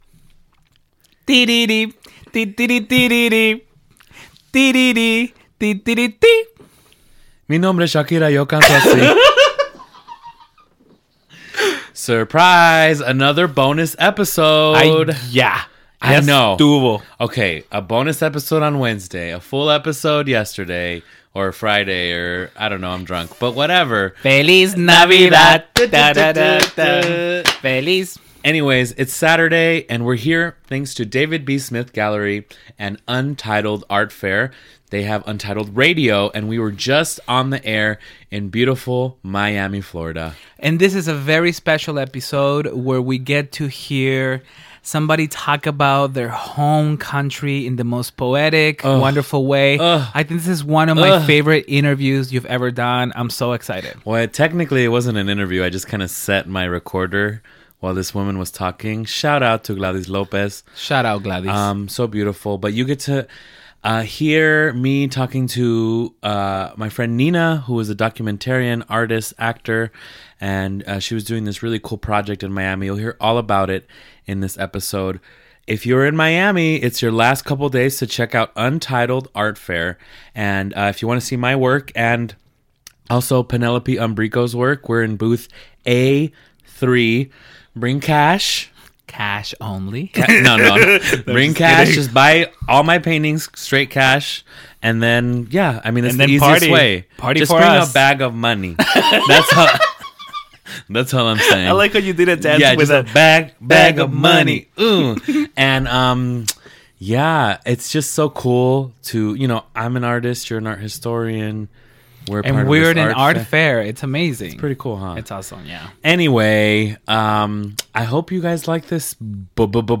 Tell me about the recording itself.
Today we will broadcast an episode recorded for this edition of the fair: